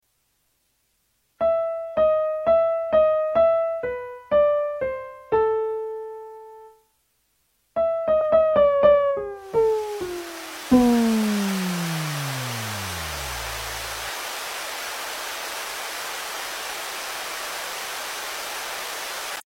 Für Elise - Beethoven piano tutorial Classical Music Is Normally Boring Sound Effects Free Download.